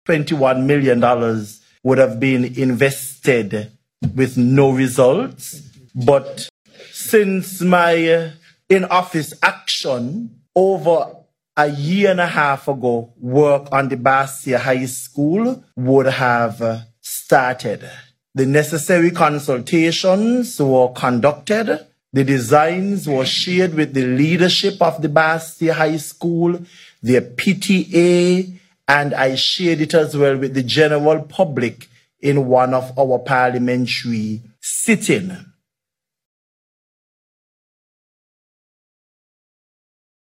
Deputy Prime Minister and Minister of Education-St. Kitts, the Hon. Dr. Geoffrey Hanley, gave an update on the reconstruction plans for the new Basseterre High School on Mar. 6th.